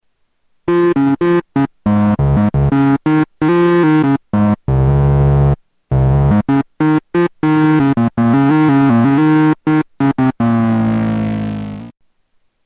Tags: Sound Effects EML ElectroComp 101 EML101 ElectroComp 101 Synth Sounds